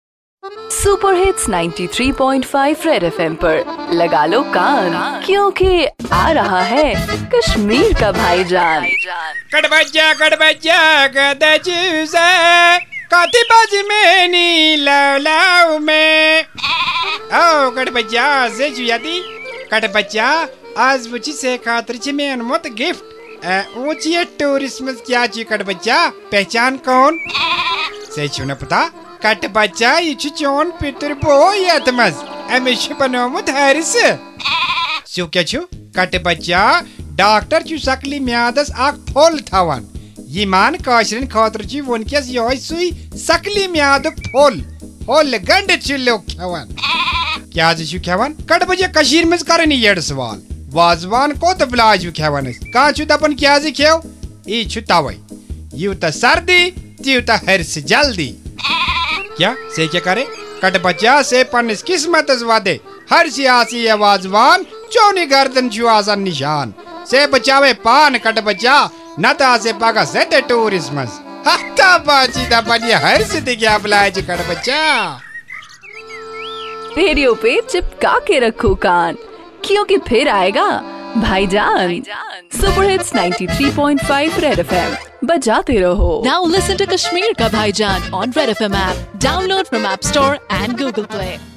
Bhaijaan the ultimate dose of comedy in Kashmir which is high on satire and humor